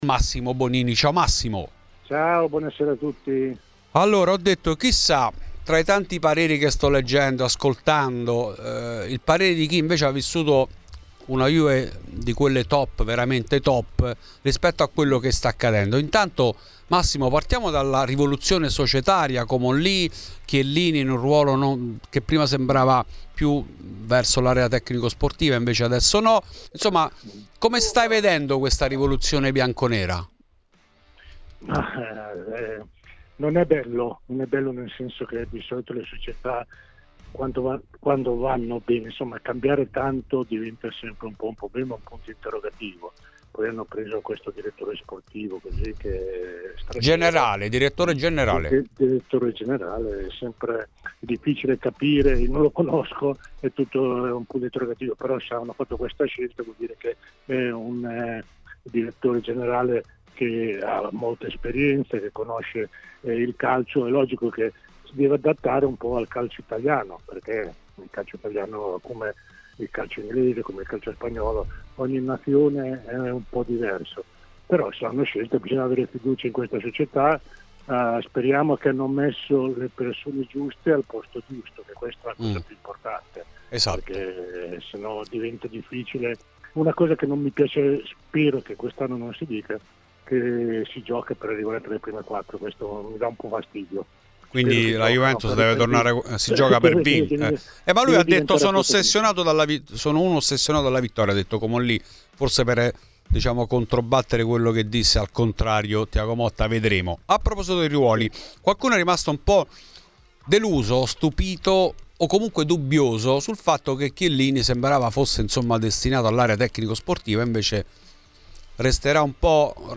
In ESCLUSIVA a Fuori di Juve Massimo Bonini.